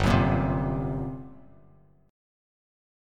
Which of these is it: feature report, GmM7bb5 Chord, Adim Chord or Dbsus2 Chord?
GmM7bb5 Chord